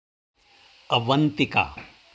Avantika (uh-VUHN-tih-kaa)